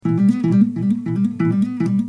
描述：古典吉他的敲击声
Tag: 140 bpm Classical Loops Guitar Electric Loops 354.04 KB wav Key : Unknown